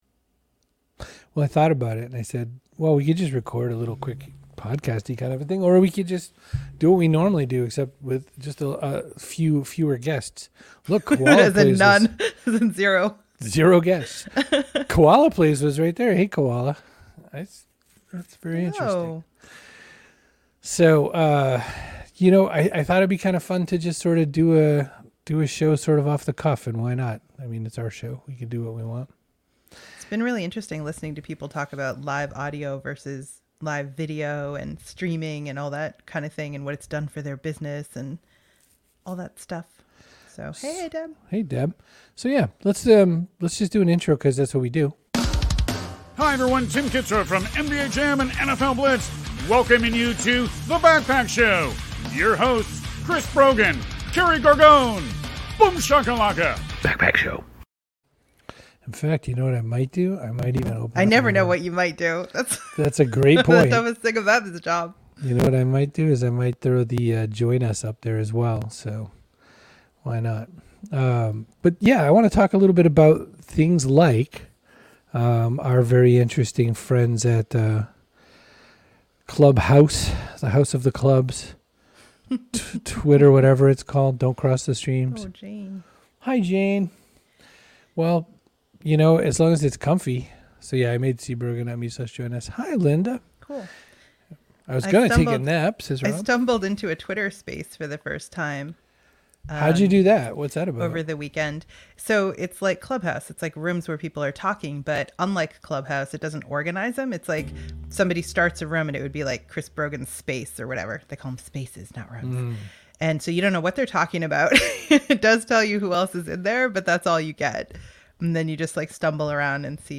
just talk with YOU in an impromptu episode